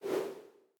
whoosh.ogg